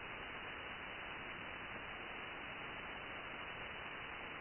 blank.ogg